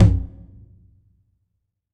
9MIDTOM.wav